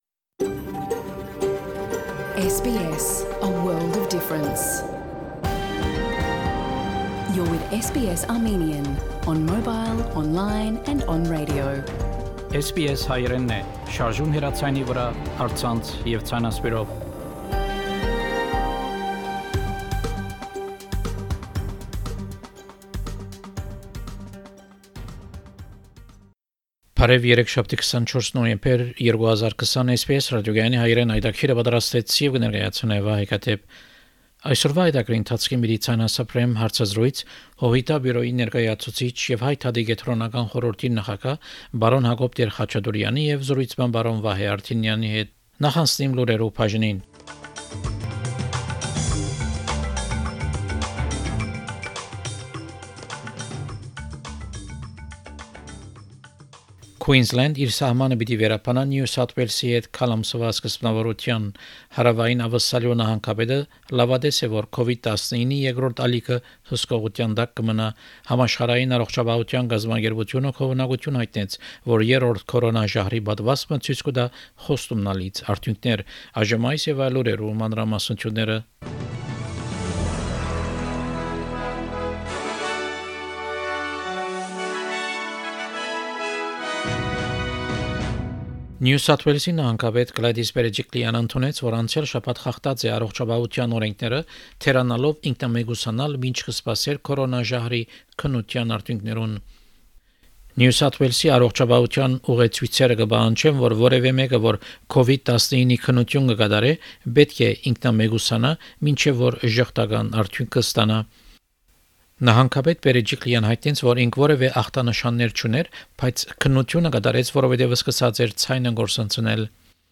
SBS Armenian news bulletin from 24 November 2020 program.